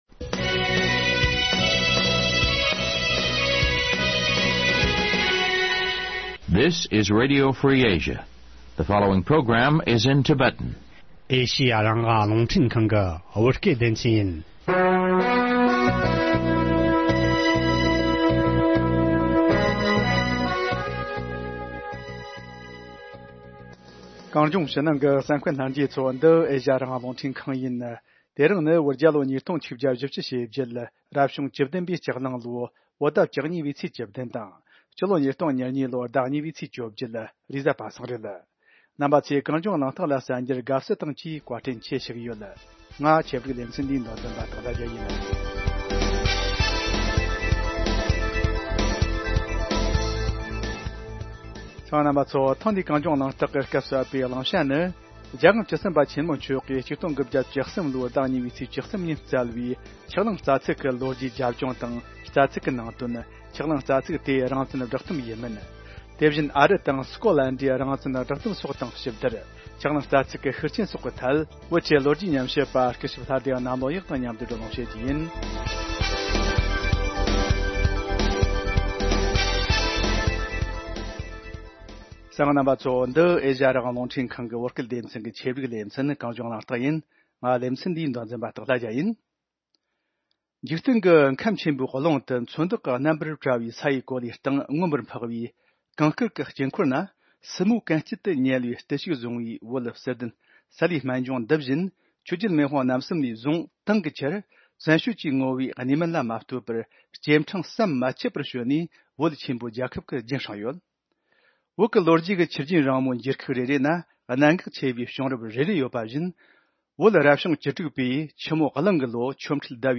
བགྲོ་གླེང་བྱེད་རྒྱུ་ཡིན།